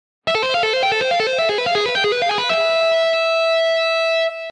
Гитарное упражнение 5
Аудио (100 УВМ)